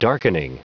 Prononciation du mot darkening en anglais (fichier audio)
Prononciation du mot : darkening